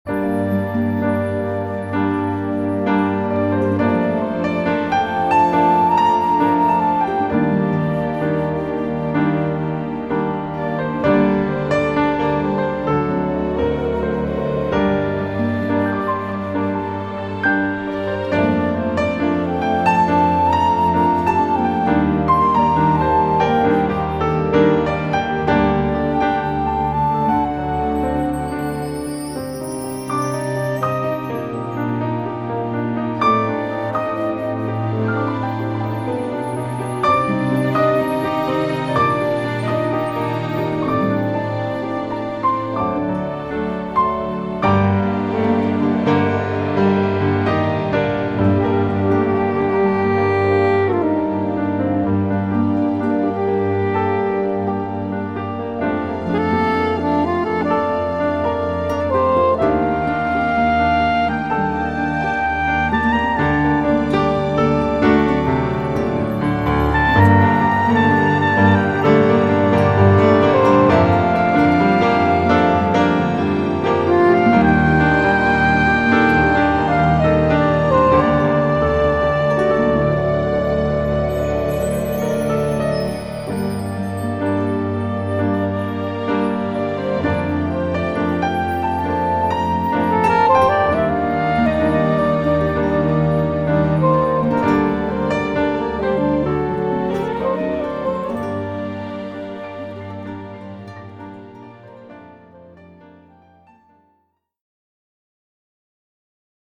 ヒーリングＣＤ
優しいピアノの音がリラックス効果を高めます。